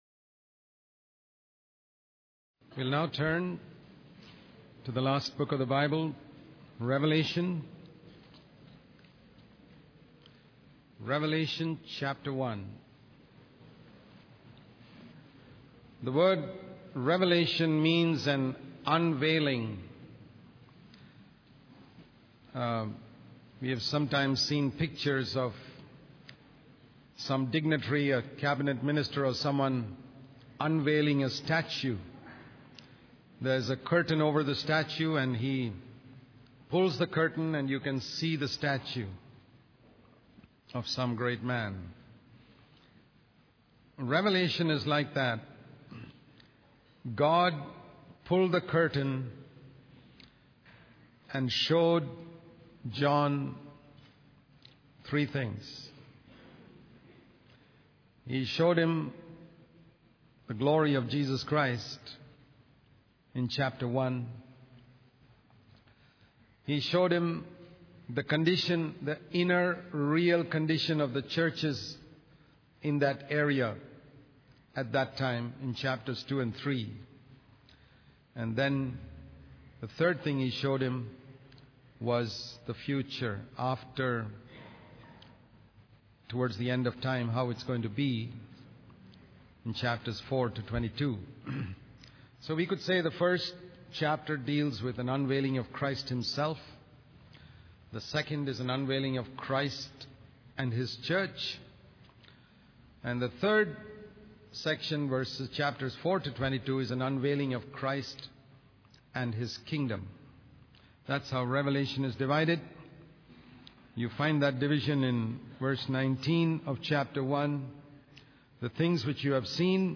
In this sermon, the speaker discusses the book of Revelation and the seven glimpses of heaven found within it. He emphasizes the importance of praising and worshiping the Lord in order to prepare for heaven. The speaker also explains the concept of redemption, using the example of Boaz redeeming Ruth and her land.